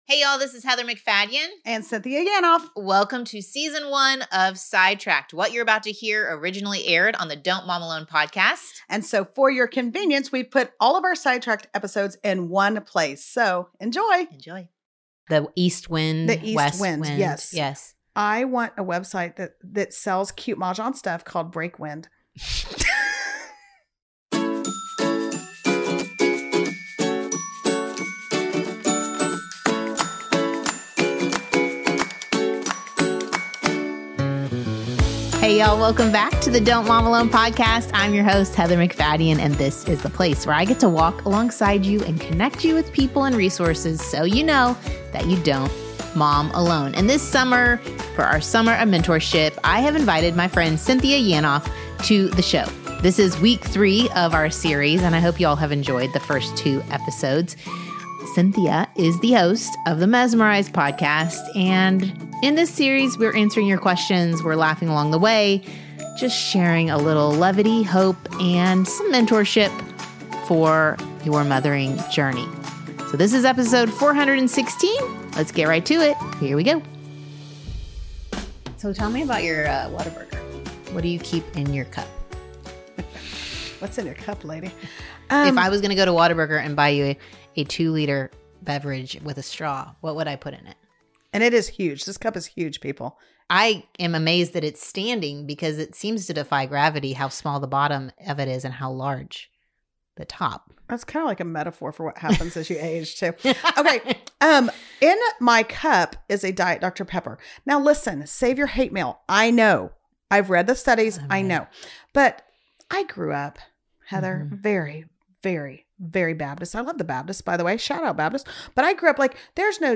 My guest for these 6 weeks is both wise AND funny.